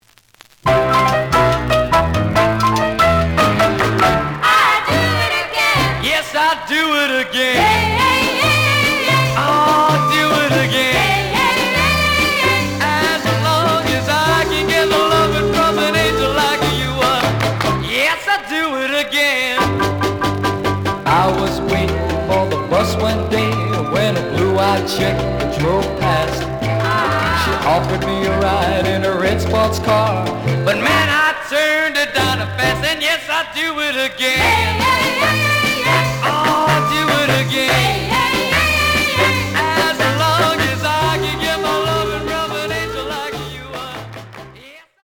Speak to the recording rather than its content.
The audio sample is recorded from the actual item. Slight noise on B side.